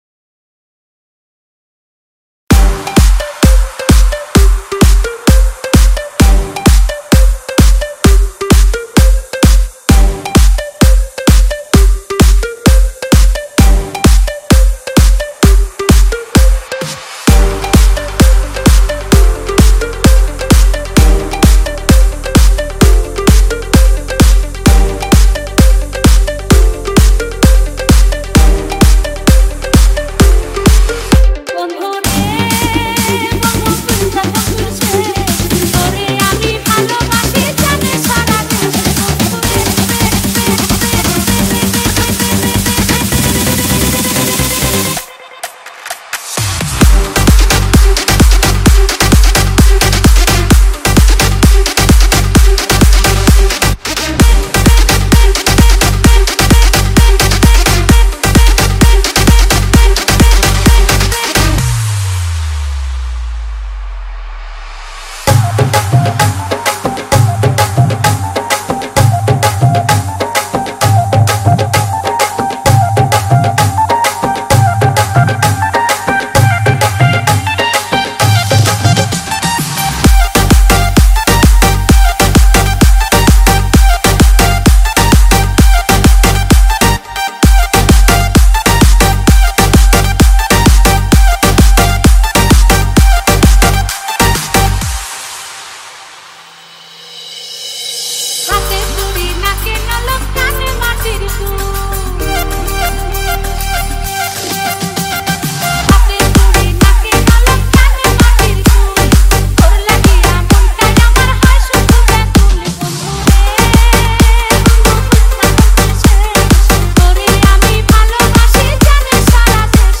Category : Bangla Remix Song